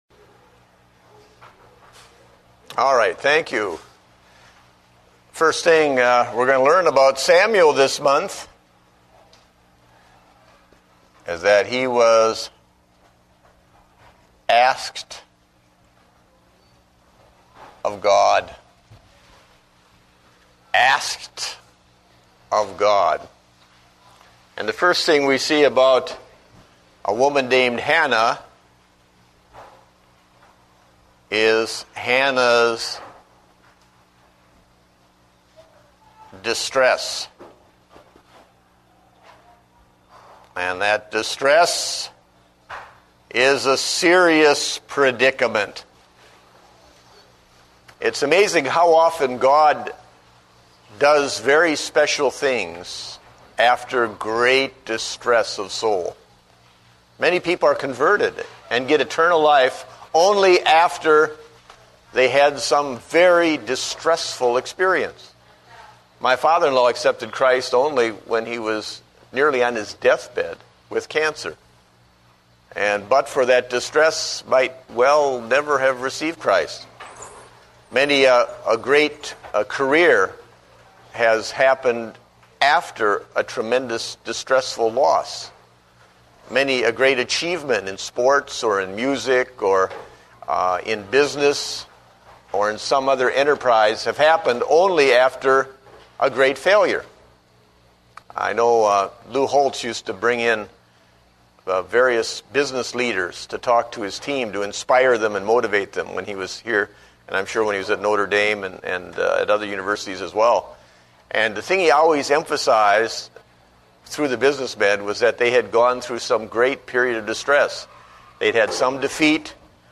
Date: September 6, 2009 (Adult Sunday School)